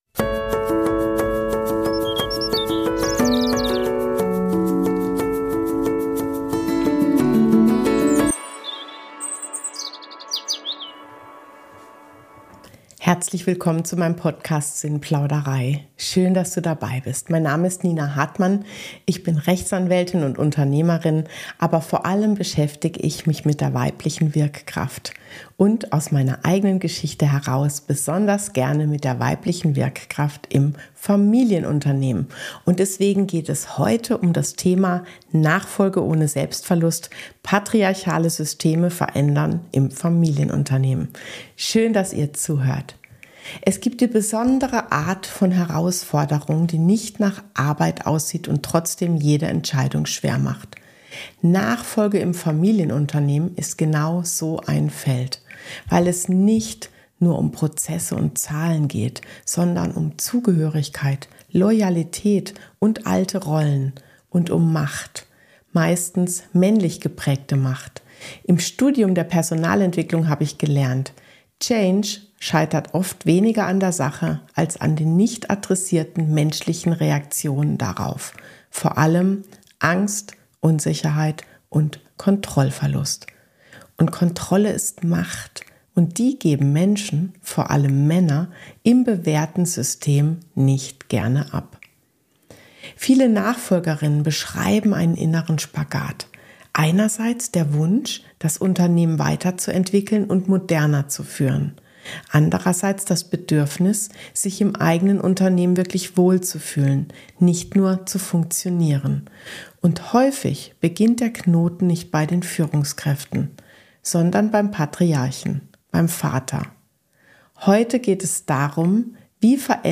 In dieser Solo-Folge geht es darum, wie Veränderung in einem patriarchalen System gelingen kann, ohne dass die Nachfolgerin sich selbst verliert – und ohne dass das Unternehmen in Loyalitätskriegen, Schattenhierarchien oder Dauer-Sabotage landet.